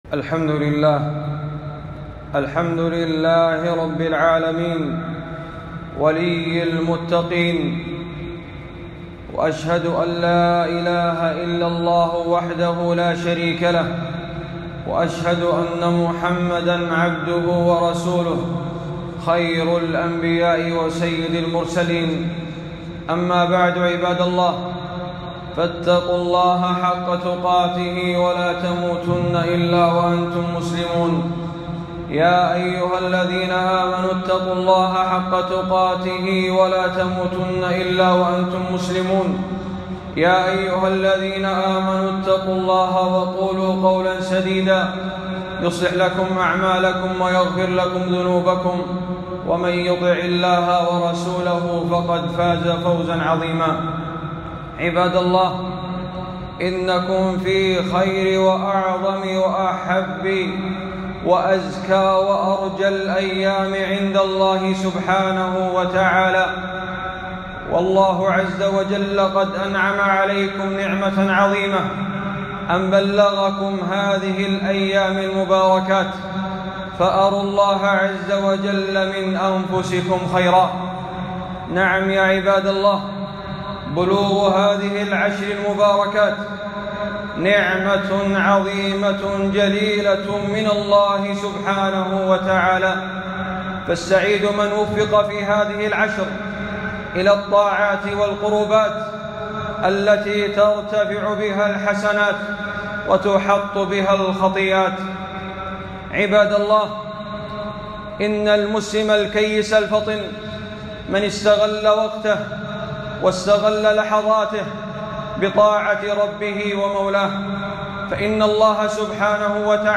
خطبة - الحث على الصالحات في الأيام المباركات 3 ذي الحجة 1441 هــ